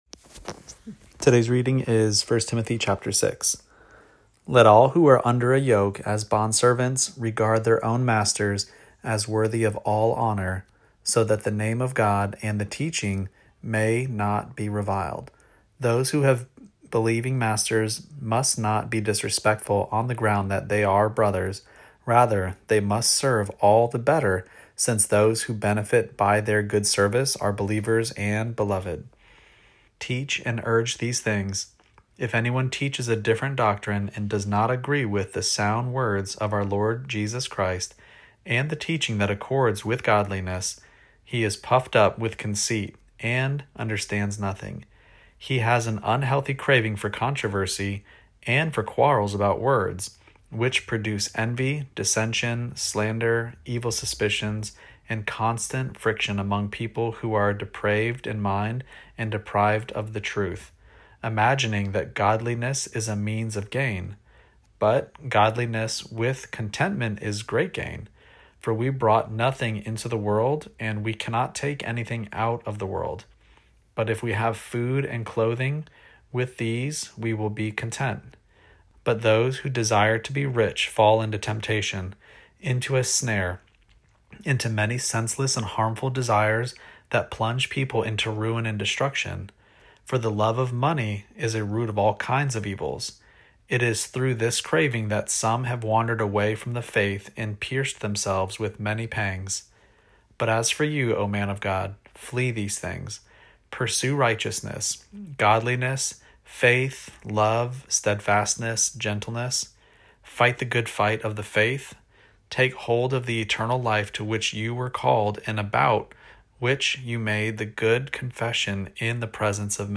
Daily Bible Reading (ESV) October 26: 1 Timothy 6 Play Episode Pause Episode Mute/Unmute Episode Rewind 10 Seconds 1x Fast Forward 30 seconds 00:00 / 3:29 Subscribe Share Apple Podcasts Spotify RSS Feed Share Link Embed